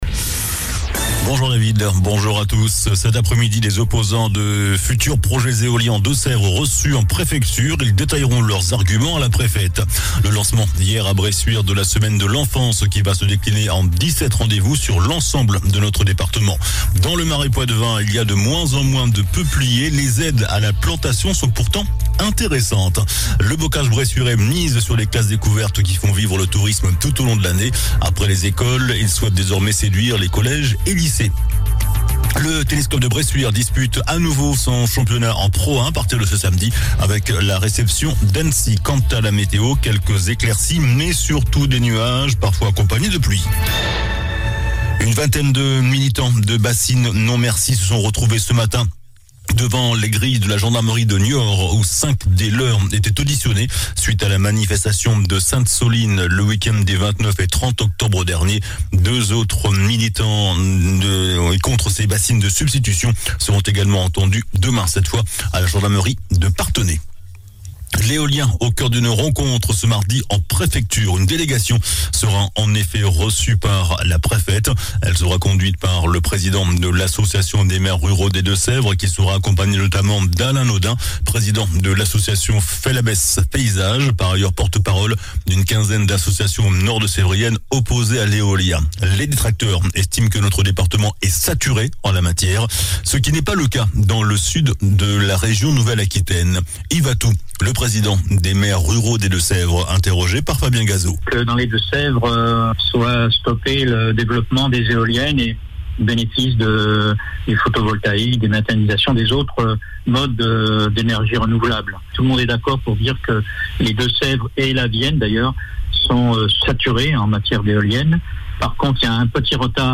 JOURNAL DU MARDI 15 NOVEMBRE ( MIDI )